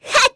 Pansirone-Vox_Attack2_kr.wav